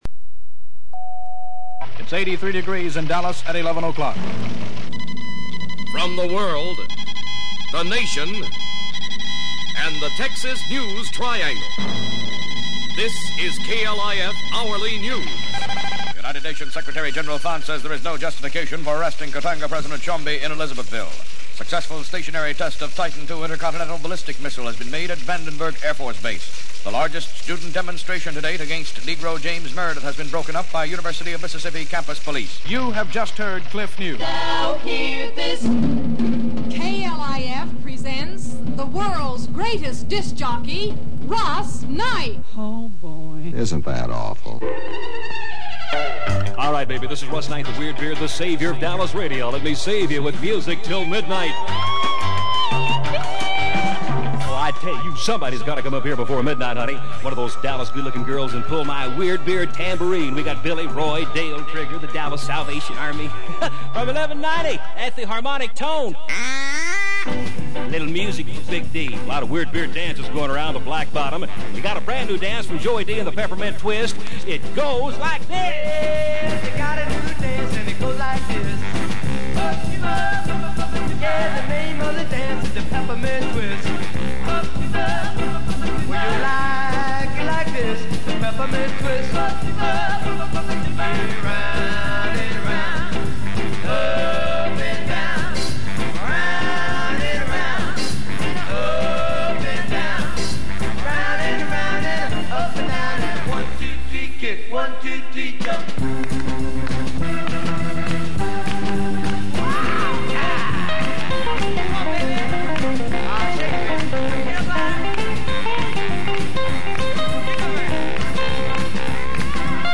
AM Radio from KLIF in Dallas 1962 (Part 1)